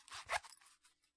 拉链 " zipper2
描述：金属拉链从钱包或裤子被拉开
Tag: 金属 钱包 裤子 拉链 拉链